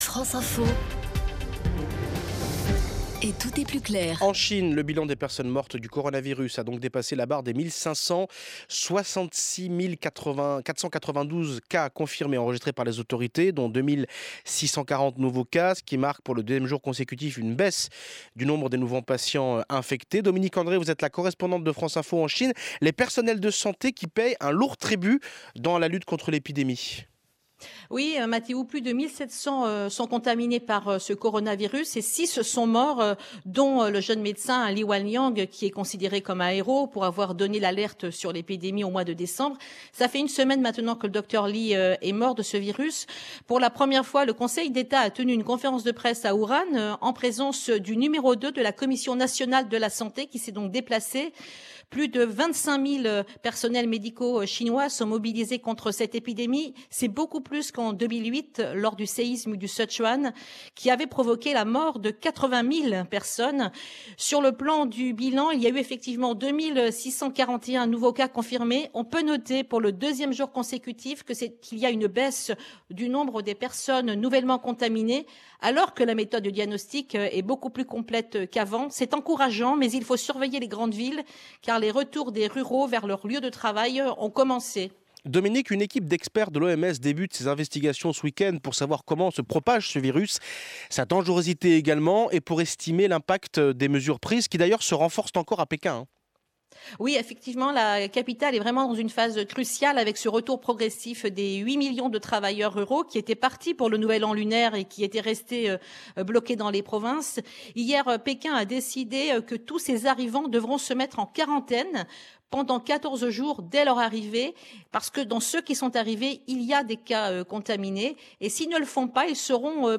Interview vidéo